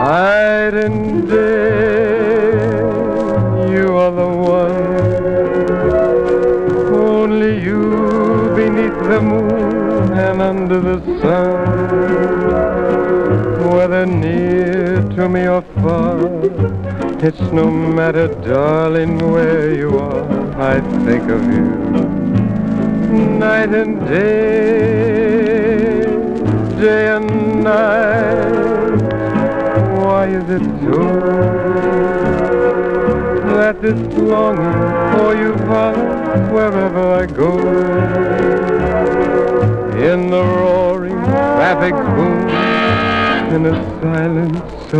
Jazz, Swing　USA　12inchレコード　33rpm　Mono